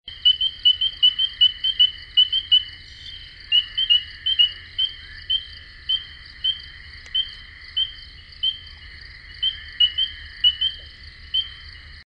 Northern Spring Peeper
Males emit a high, piping whistle or “peep” once every second during breeding season.
Males also make a lower-pitched trilling whistle when another male moves too close to its calling site.
northern-spring-peeper-call.mp3